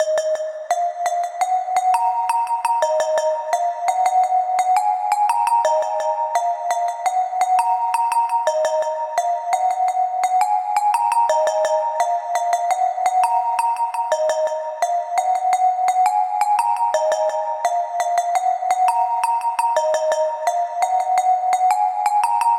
描述：嘻哈慢节奏985bpm
标签： 85 bpm Hip Hop Loops Drum Loops 1.90 MB wav Key : Unknown
声道立体声